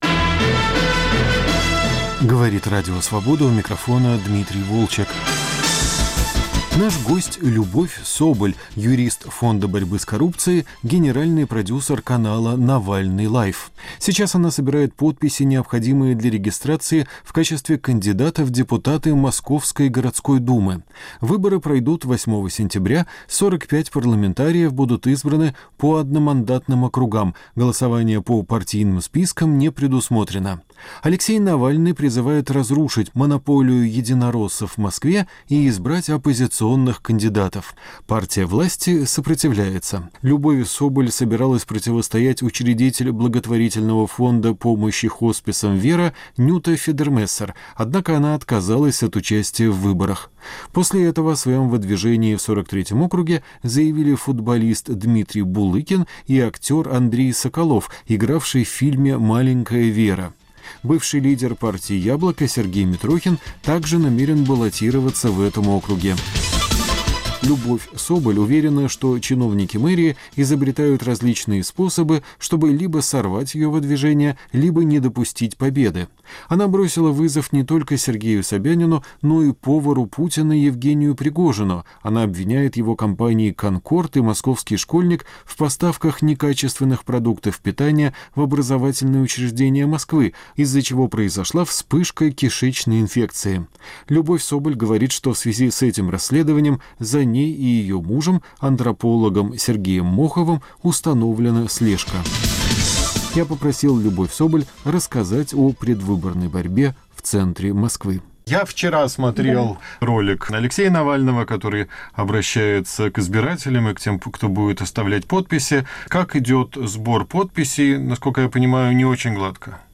Предвыборный разговор с юристом ФБК Любовью Соболь